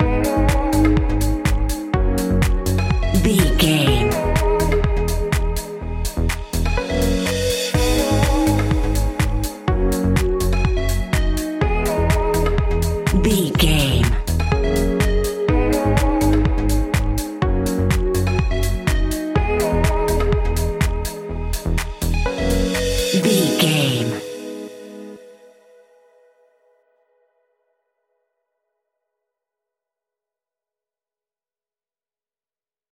Aeolian/Minor
G#
groovy
uplifting
futuristic
driving
energetic
repetitive
drum machine
synthesiser
piano
electro house
funky house
synth leads
synth bass